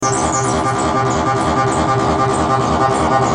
Sons Roland Tb303 -3
Basse tb303 - 52